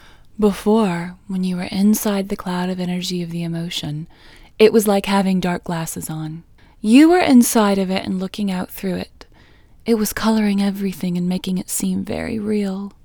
OUT Technique Female English 18